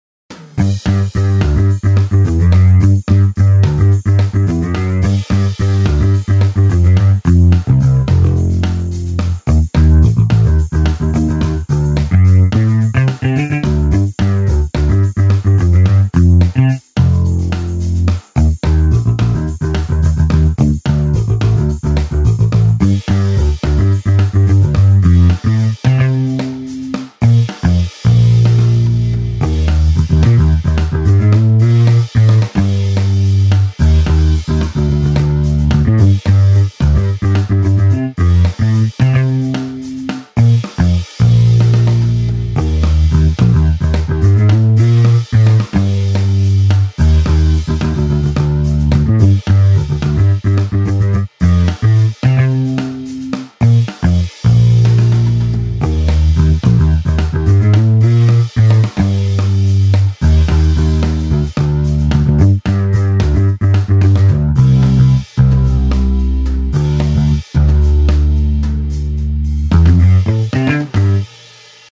SR Hofn Bass